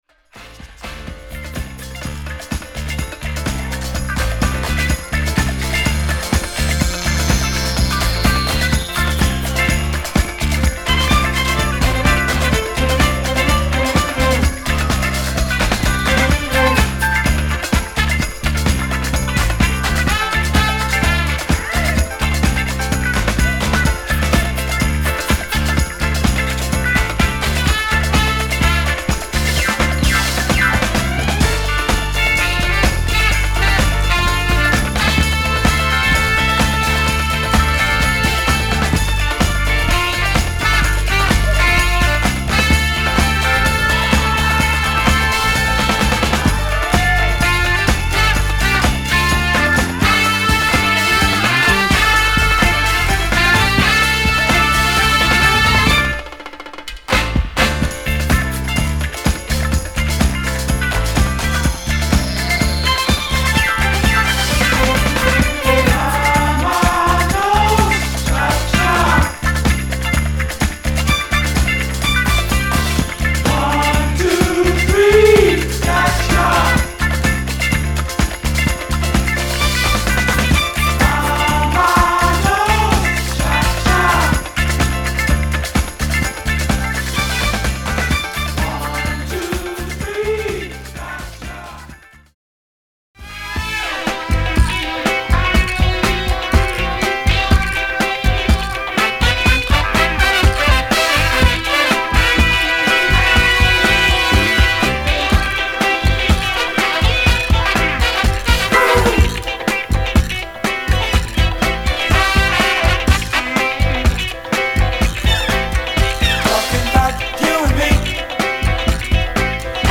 チリチリの箇所あります。